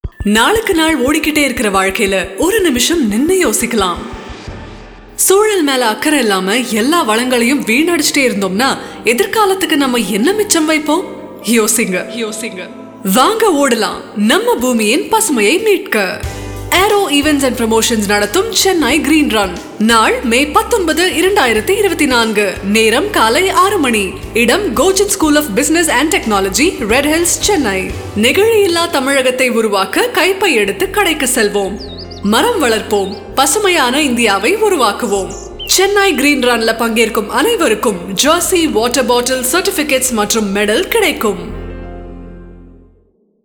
Chennai Green Run – Radio Commercial